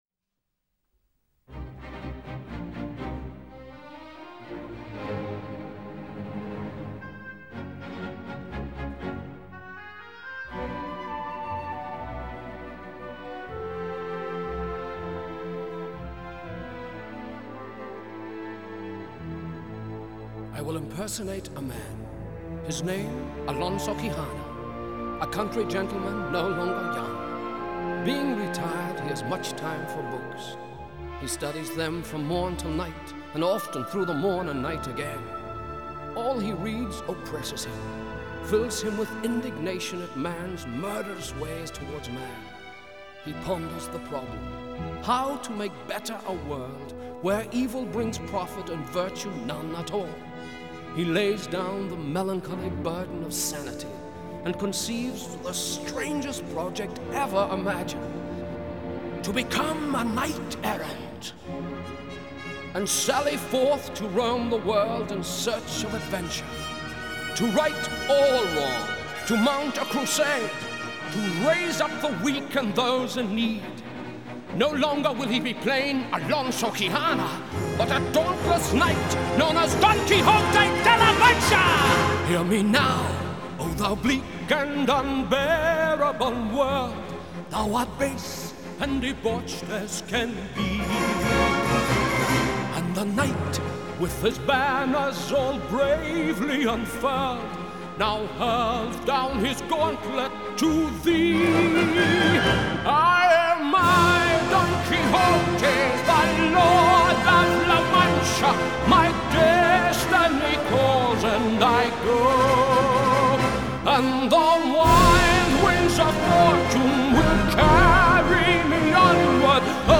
Música contemporánea
Canto
Orquesta